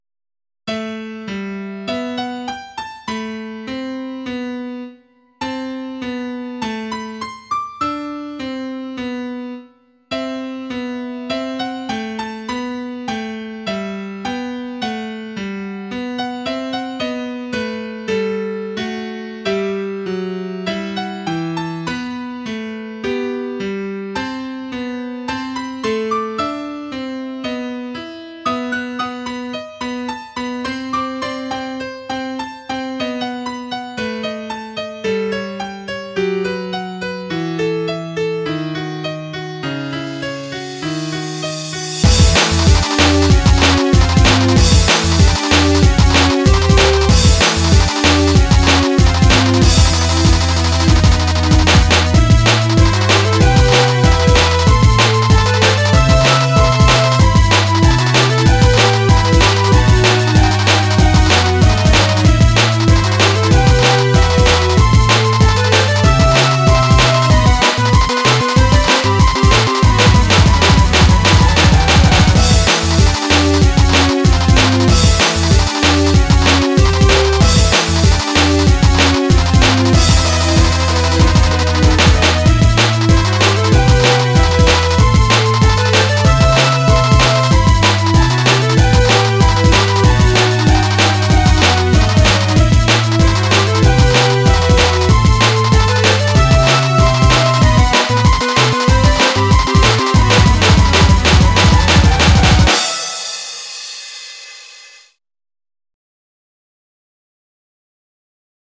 下の曲は前奏長いので注意してね